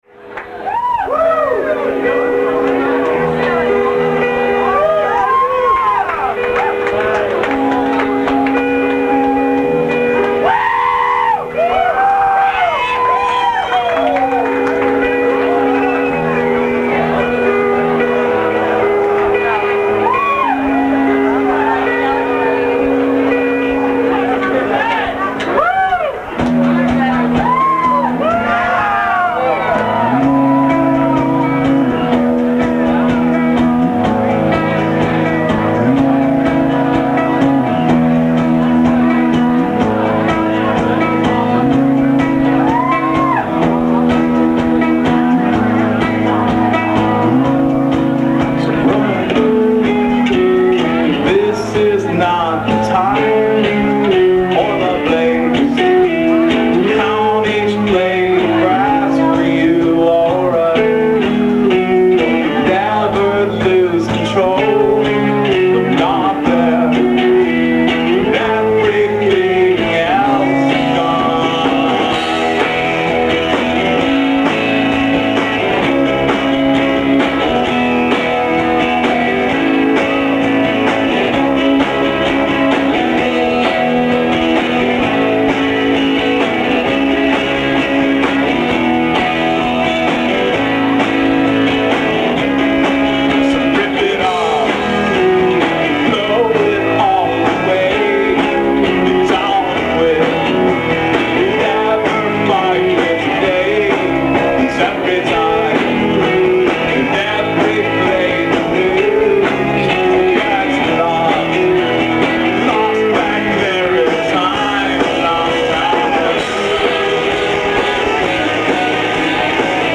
4 songs from a set at the Empty Bottle